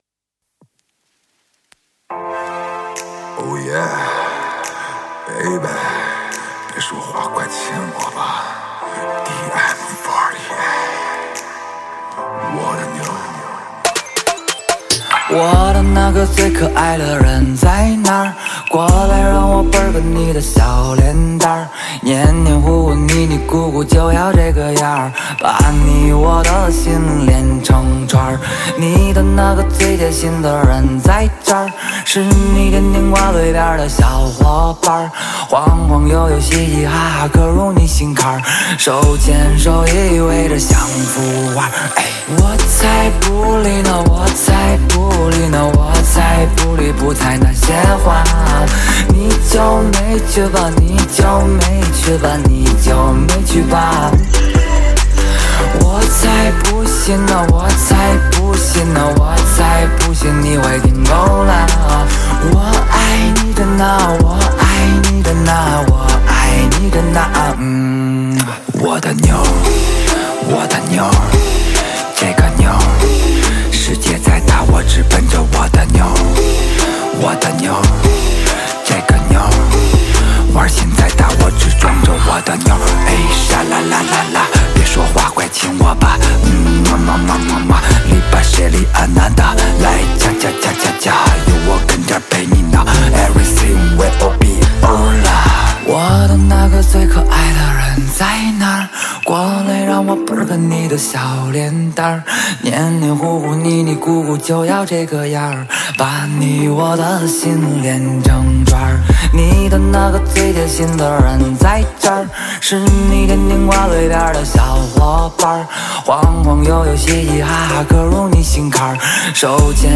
运用了百分之八十的各种EDM编曲方式
China Dance Music，有欧美音乐的质感与动感，更要有他所喜欢的一切中式主旋和语言叙述方式。